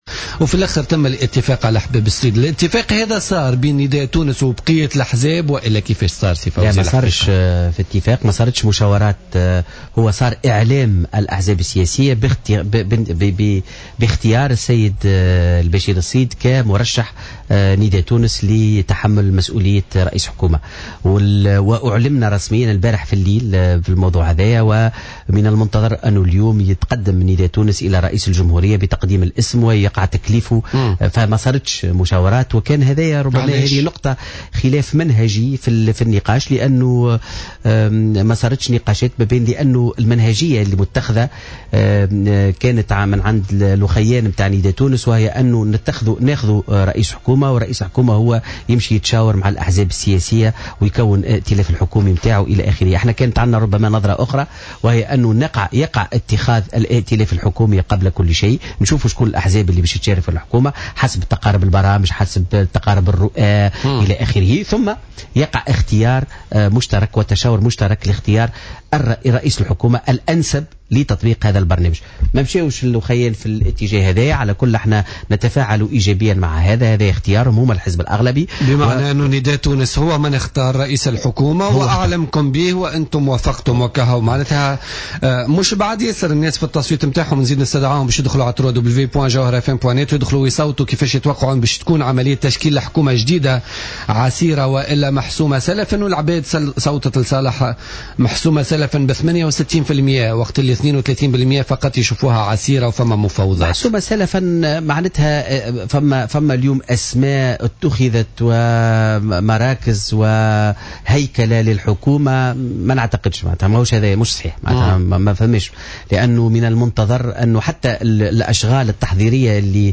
قال القيادي في حزب آفاق تونس، فوزي عبد الرحمان في تصريح للجوهرة أف أم اليوم الاثنين، إن حزبه لا يعترض على تكليف الحبيب الصيد برئاسة الحكومة بقدر احترازه على الطريقة التي كلف بها والتمشي الذي اعتمده نداء تونس.